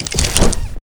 fulext_deploy.wav